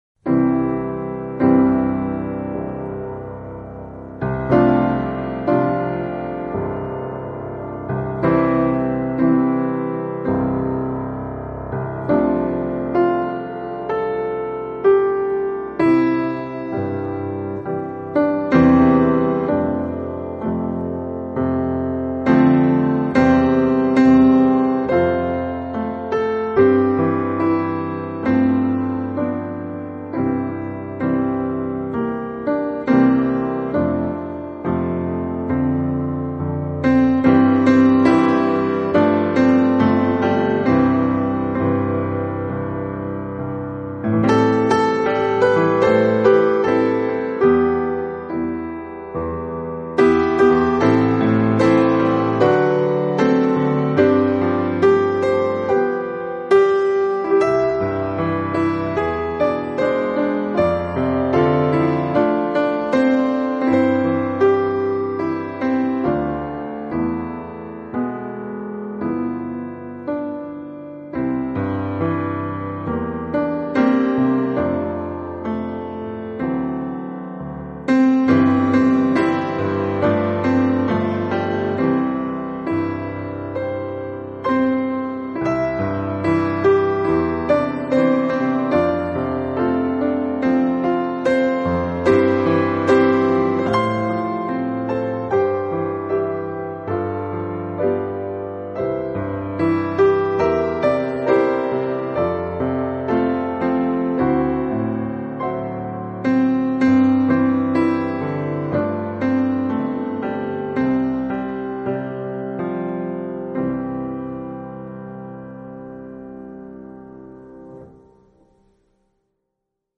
This 5-day devotional, based on Hosannas Forever by David and Barbara Leeman, features five timeless hymns—each paired with a brief reflection and the story behind its lyrics and melody.